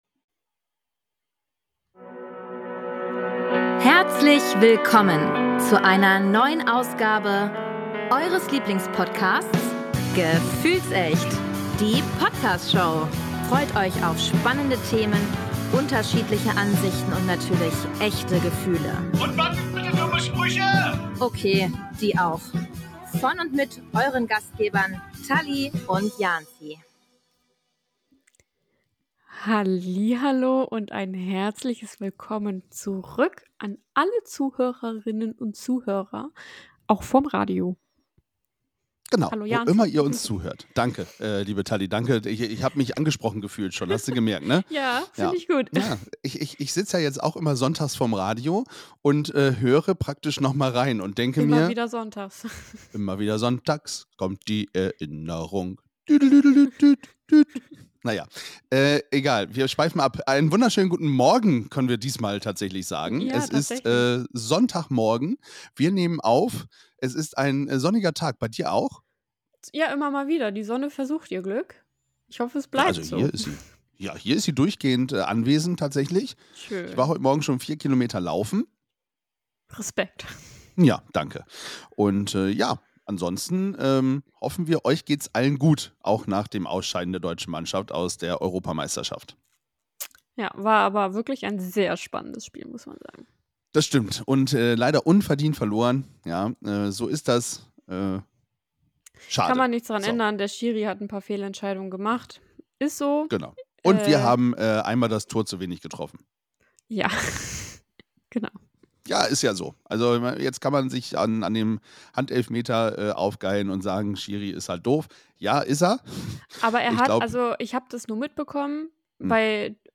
Es werden Statistiken über Frühstücksgewohnheiten und -vorlieben präsentiert, sowie Sprachnachrichten von Zuhörerinnen zum Thema Frühstück eingespielt. Ein besonderer Ausblick auf das Podfluencer Festival in München wird gegeben, bei dem Podcast-Begeisterte und Influencer zusammenkommen, um sich auszutauschen und zu vernetzen.